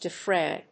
de・fray /dɪfréɪ/
発音記号
• / dɪfréɪ(米国英語)